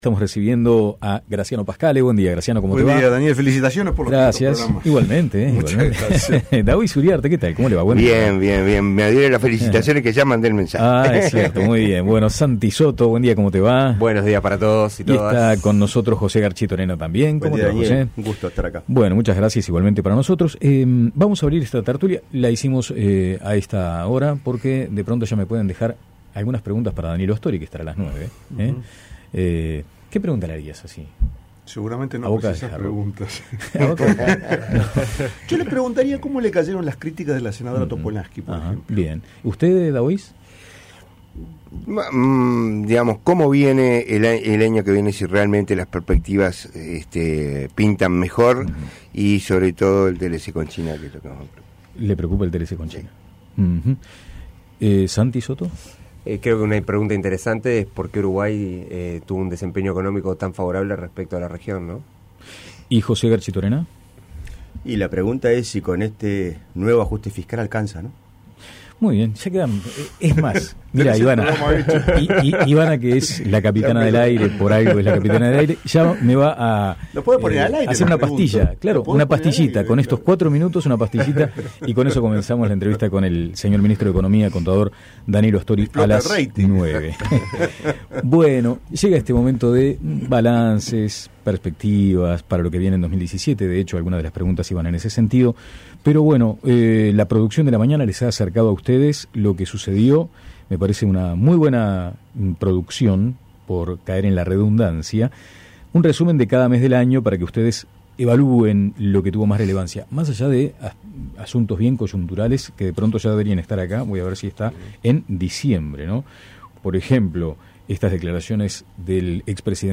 Un balance sobre el 2016 y perspectivas para el 2017. Sobre esto trató la tertulia de hoy.